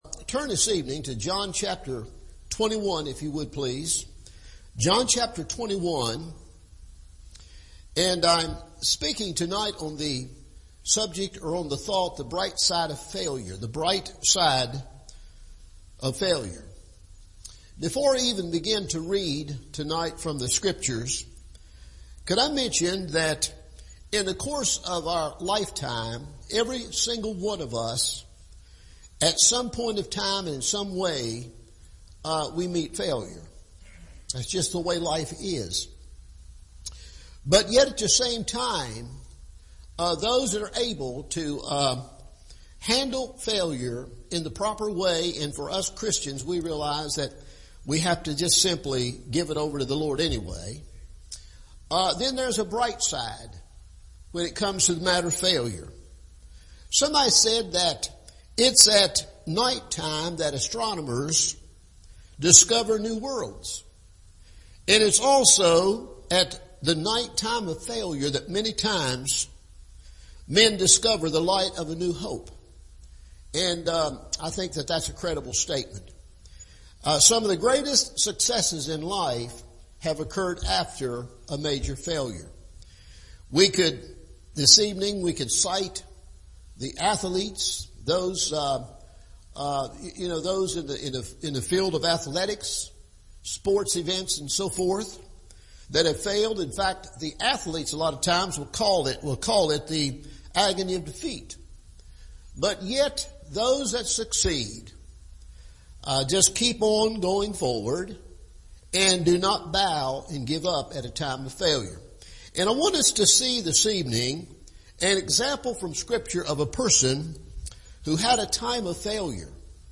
The Bright Side of Failure – Evening Service